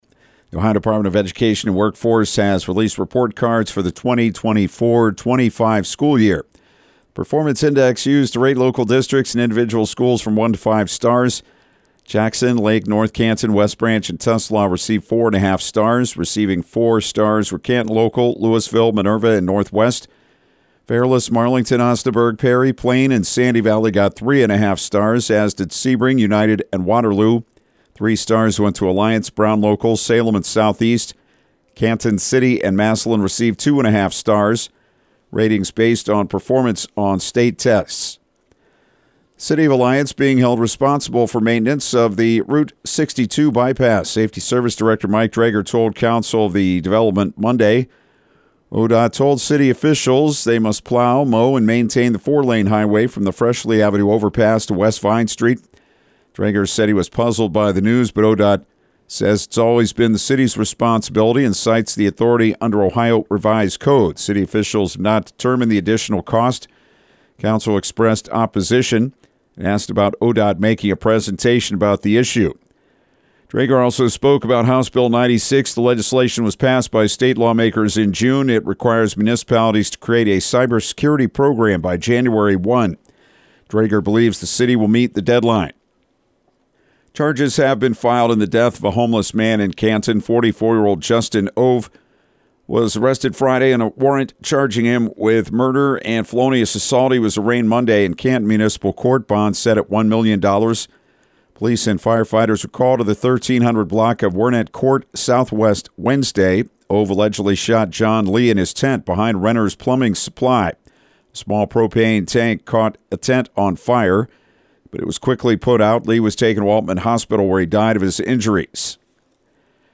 6AM-NEWS-16.mp3